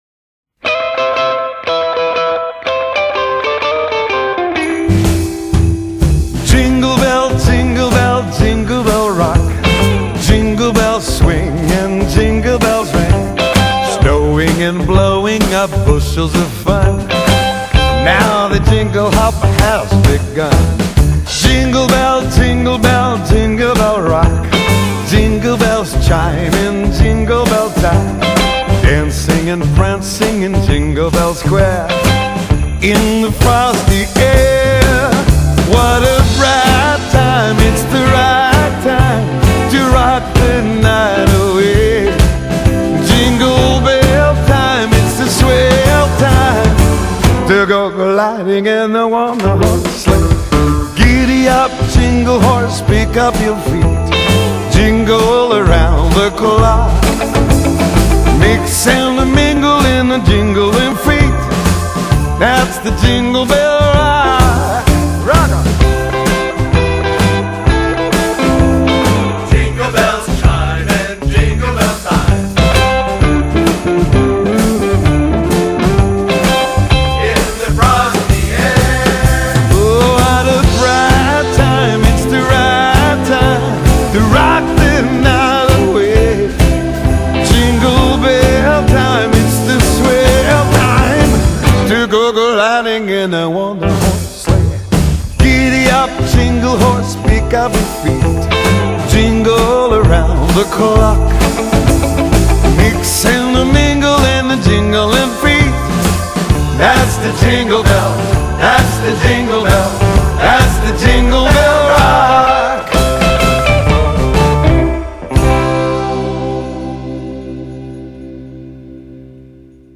Genre: Christmas, Pop, French Chanson
a rhythm and blues Christmas album